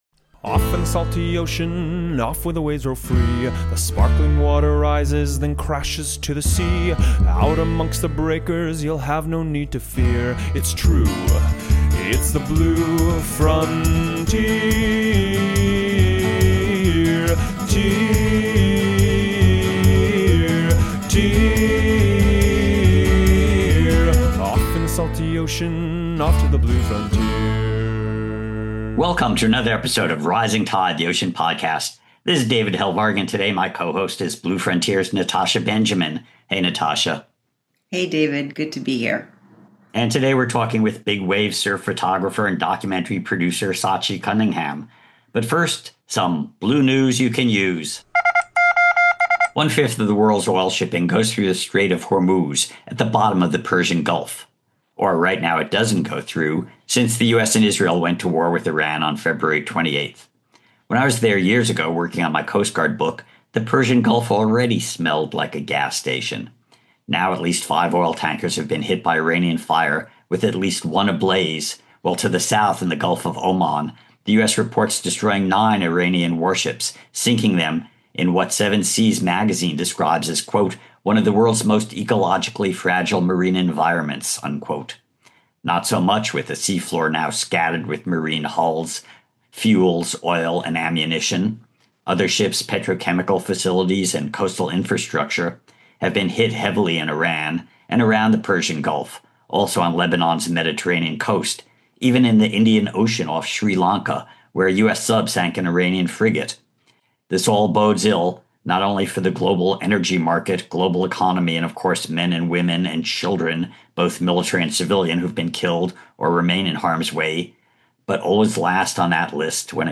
Informative, enlightening, and often humorous it is an invaluable resource for anyone passionate about understanding, enjoying, and protecting our salty blue world.